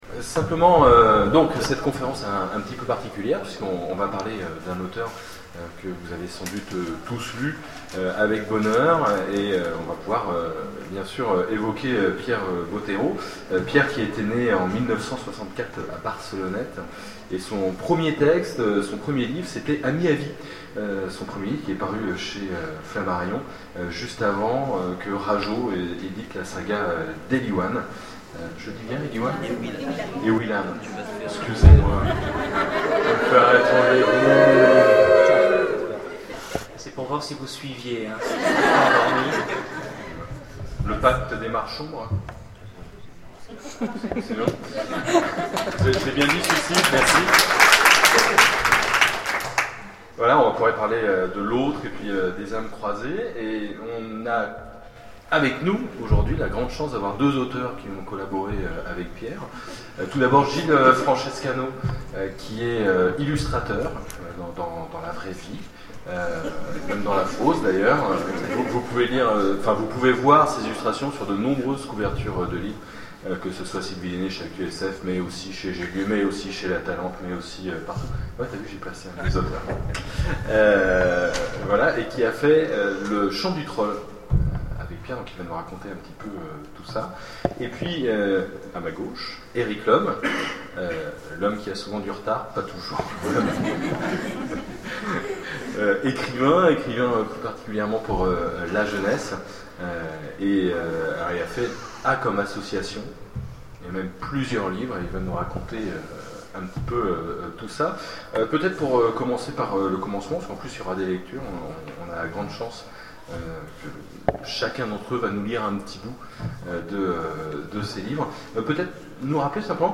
Voici l'enregistrement de la conférence en hommage à Pierre Bottero.